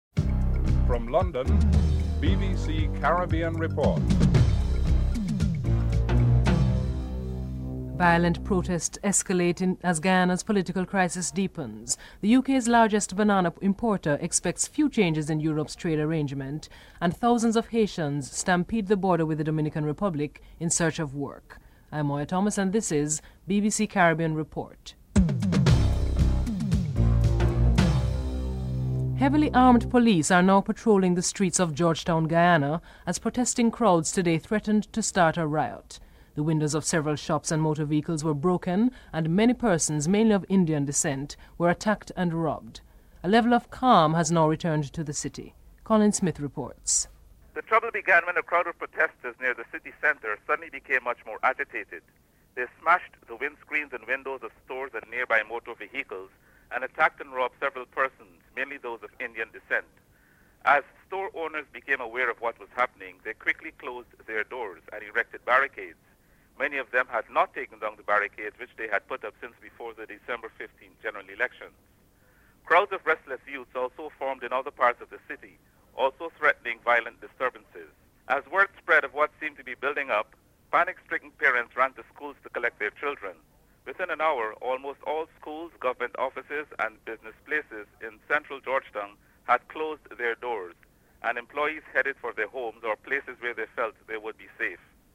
Graham Gooch talks about England's chances of beating the West Indies.